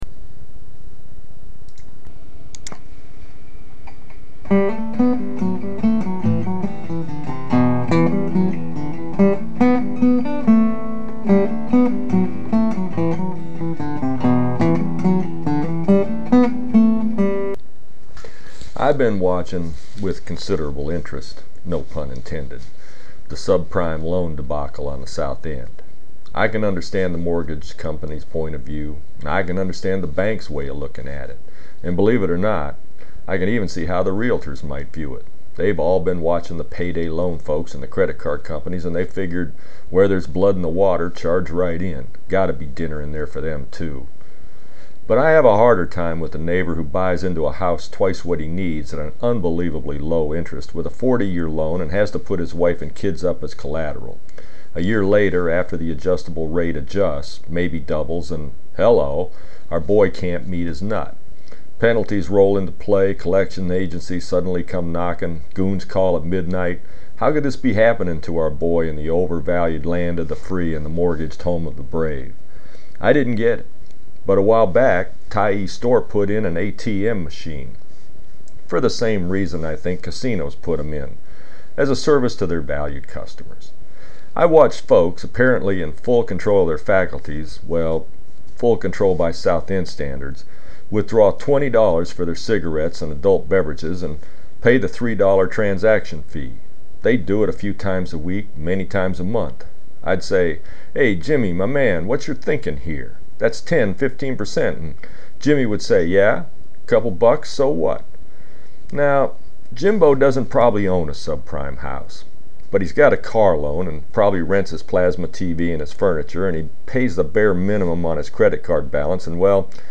tyee-atm-mathematics-with-blackberry-blossom-intro.mp3